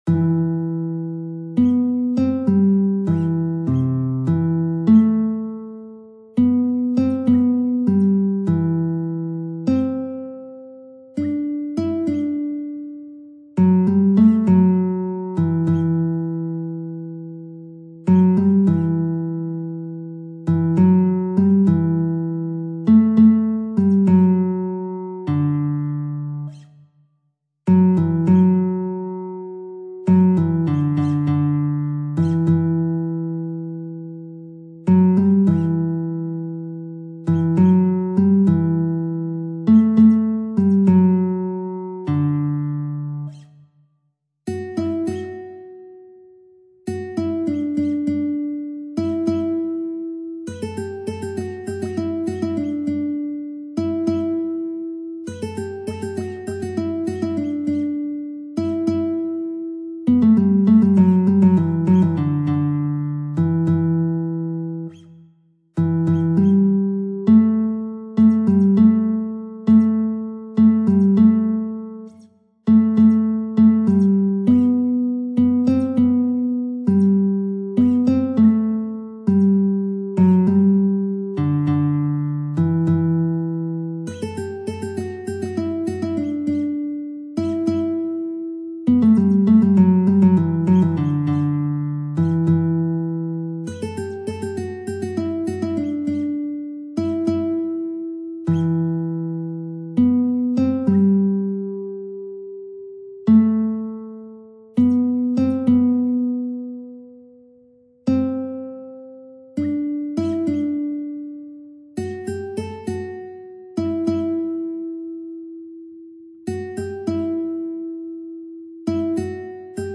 سطح : متوسط